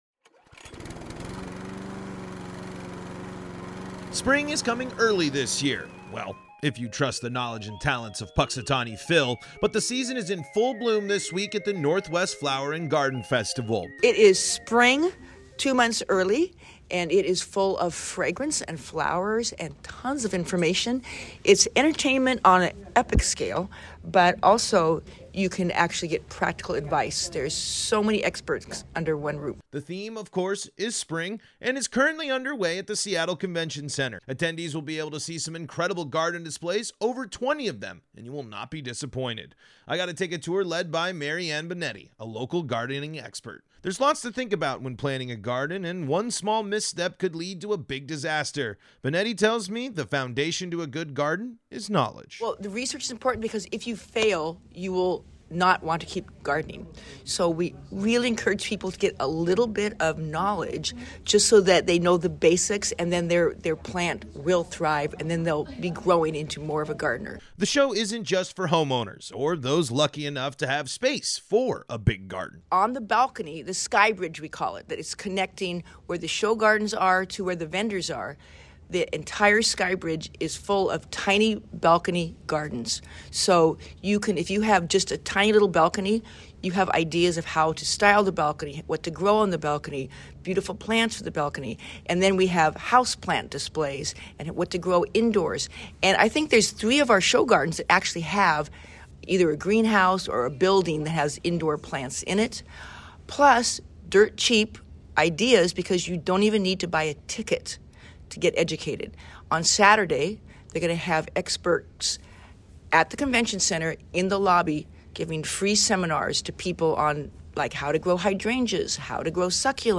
plant-and-garden-festival-feature-with-music.mp3